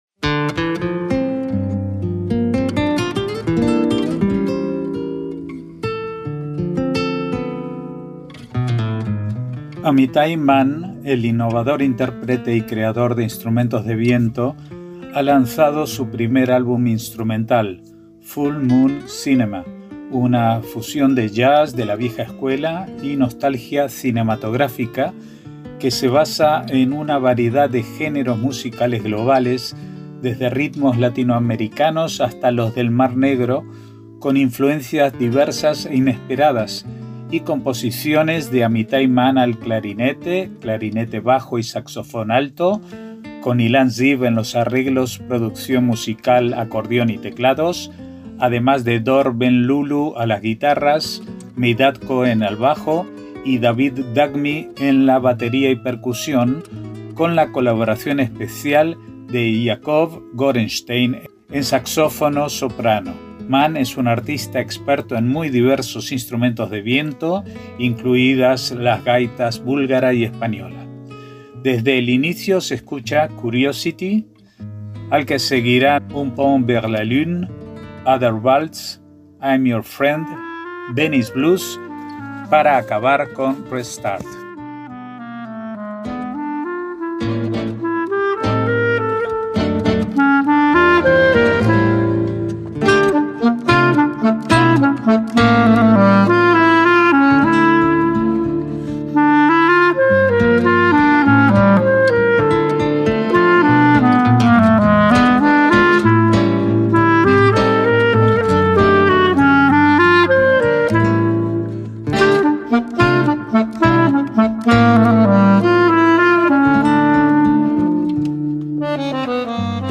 desde ritmos latinoamericanos hasta los del Mar Negro
clarinete, clarinete bajo y saxofón alto
acordeón y teclados
guitarras
batería y percusión
saxofono soprano